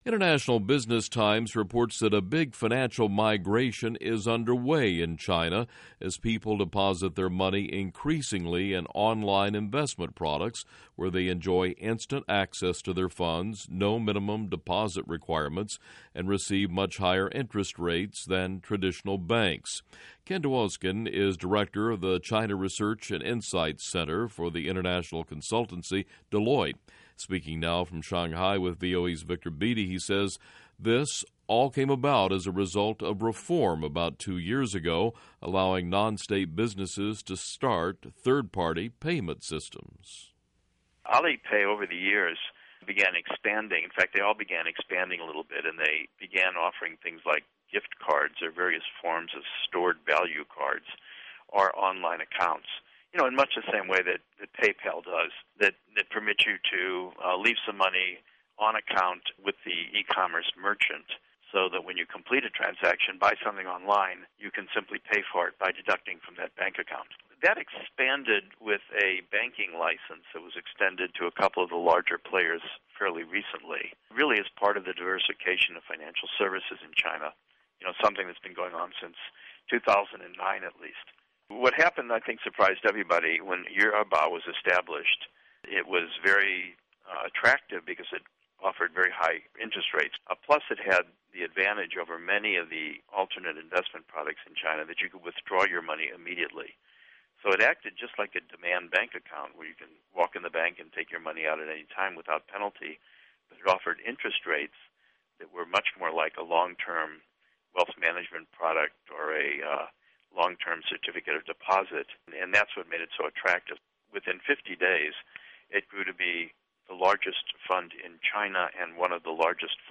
Speaking From Shanghai